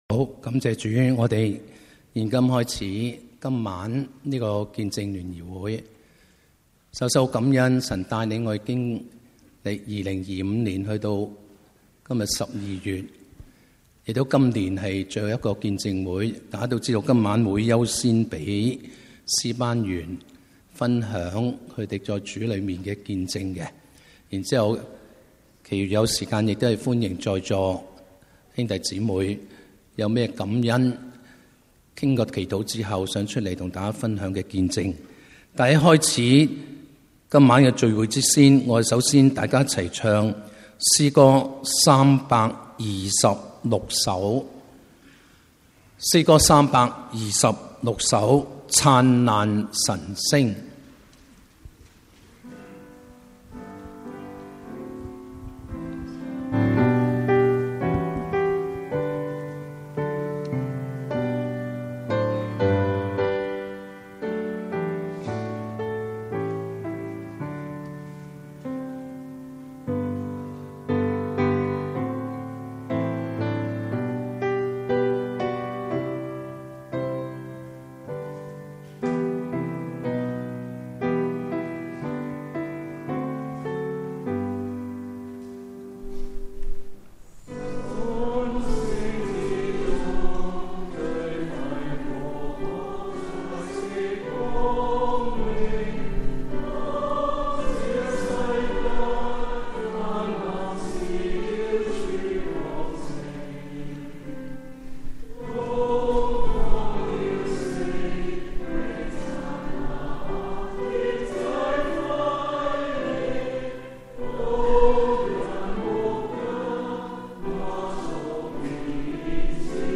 Hong Kong Testimony and Fellowship Meeting – 港九五旬節會